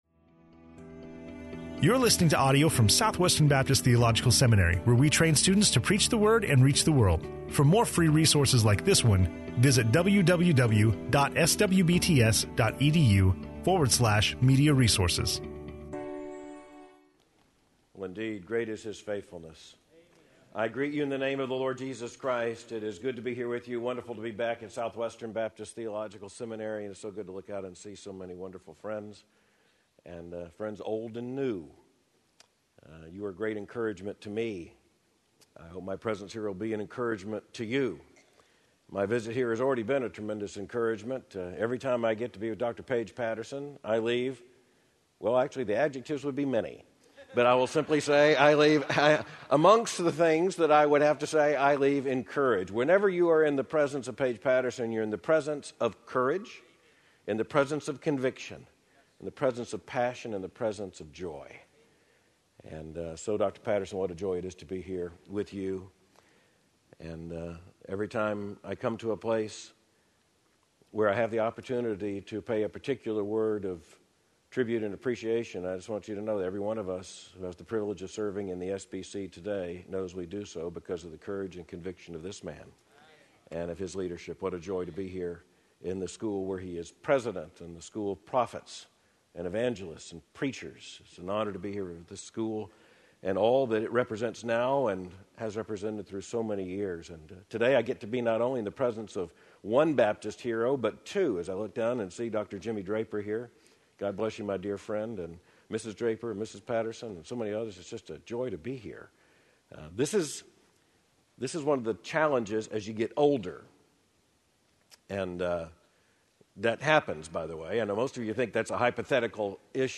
Dr. Albert Mohler speaking on John 15:18-27 in SWBTS Chapel on Thursday November 13, 2014